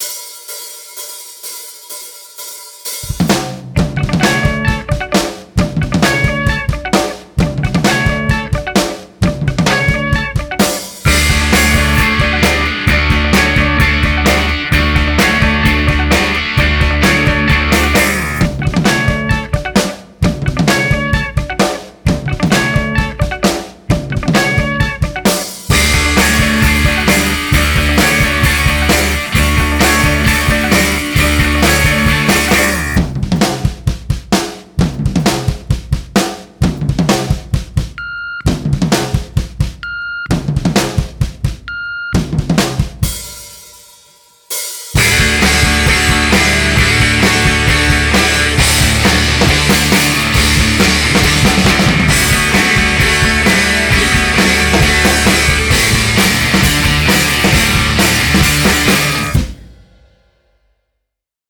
entrainant et sautillant